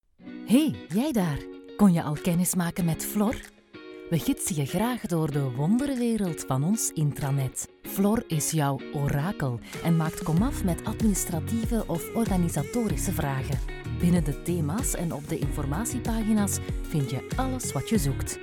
Vertrouwd, Vriendelijk, Natuurlijk
Explainer